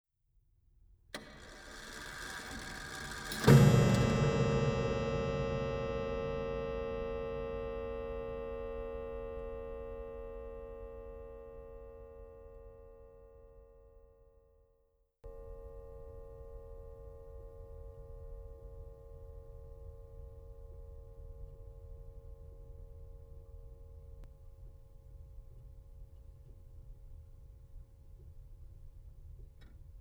Mysterium Time Chime